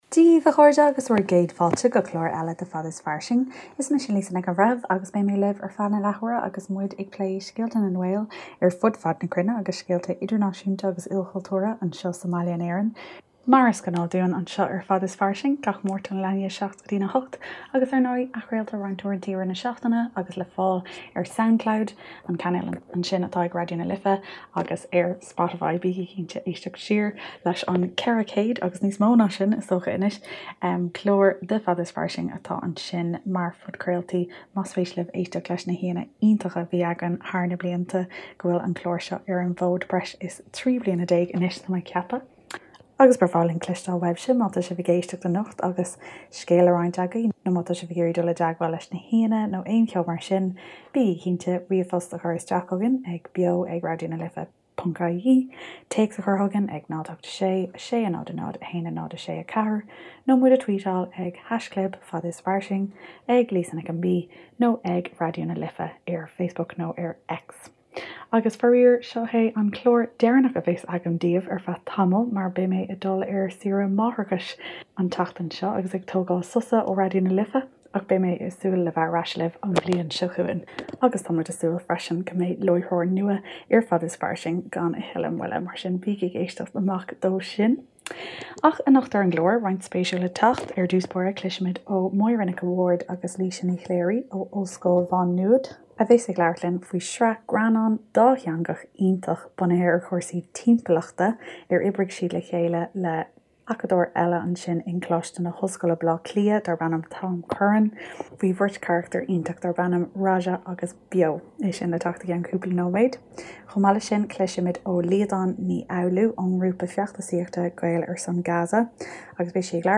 Is clár úr nua é 'Fada is Fairsing' a tosaíodh ar Raidió na Life le déanaí in aimsir Tóstáil 2013, Bliain na Gaeilge 2013 agus ag am ina bhfuil pobal na Gaeilge ag éirí níos mó agus níos mó achan lá ar fud fad an domhain. Díríonn an clár ar scéalta éagsúla ó thíortha ar fud an domhain ó chúrsaí reatha, go scéalta na Gaeilge, go scéalta grinn, le roinnt ceoil dhomhanda freisin.